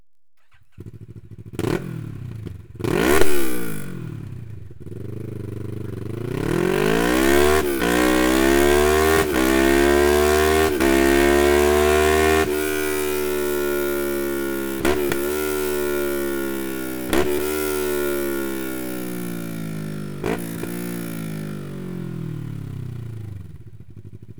Ein tieferer und sportlicherer Sound weckt noch mehr Begehrlichkeiten.
Sound Akrapovic Slip-On